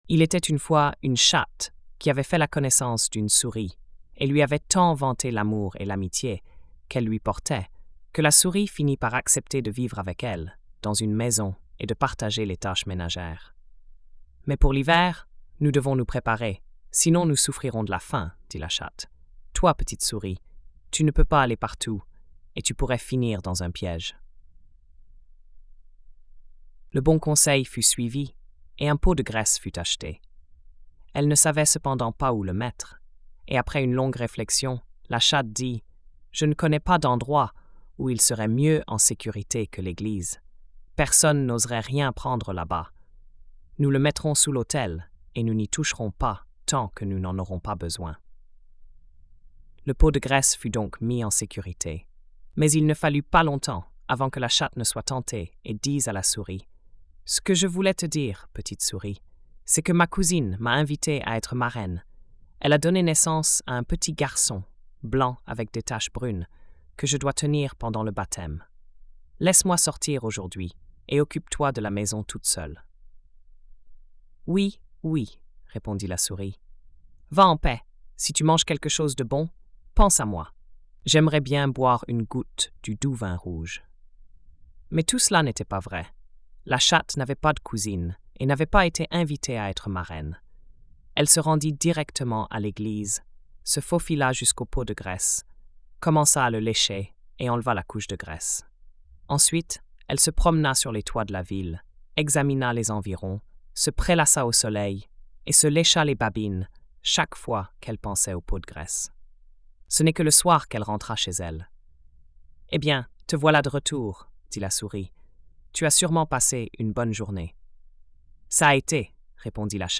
Conte de Grimm
🎧 Lecture audio générée par IA